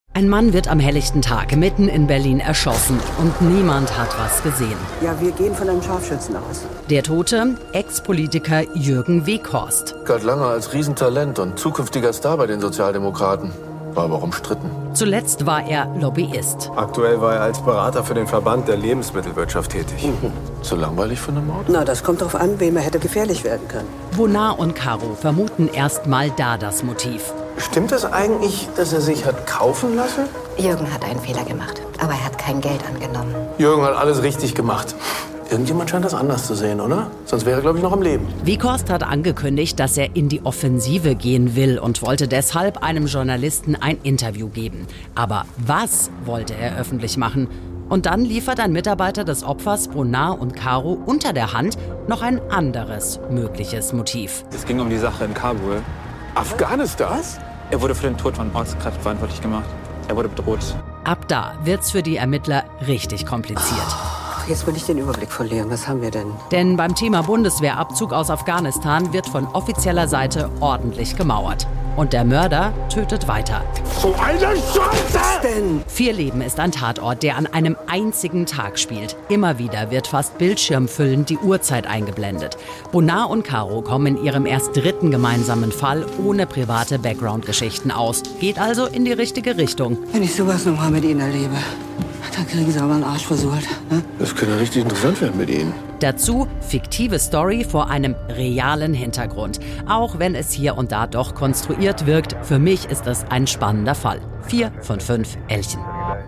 Tatort-Kritik aus dem Radio